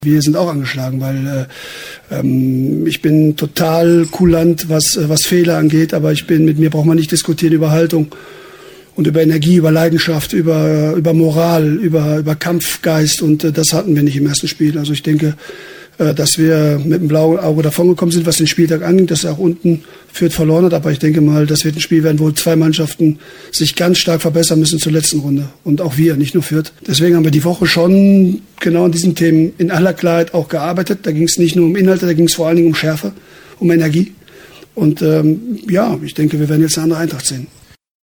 in der Pressekonferenz am Donnerstag.